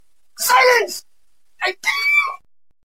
silence i kill you2 sound effects